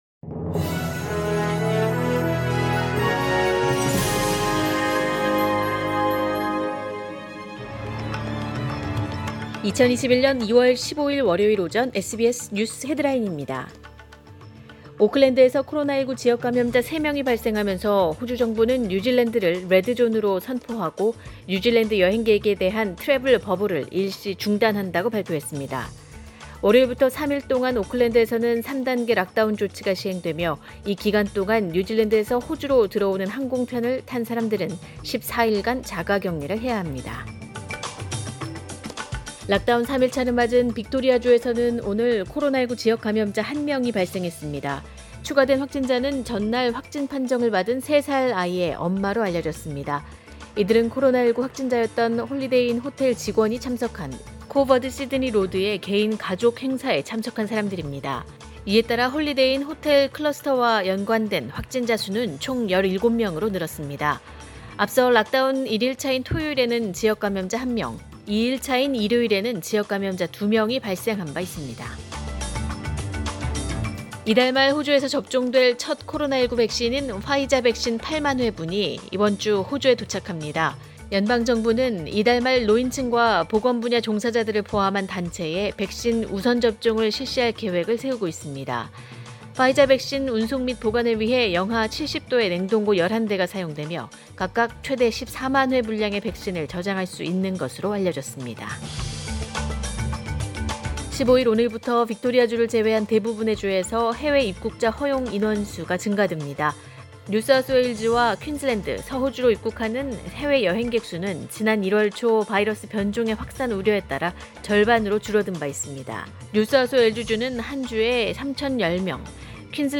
2021년 2월 15일 월요일 오전의 SBS 뉴스 헤드라인입니다.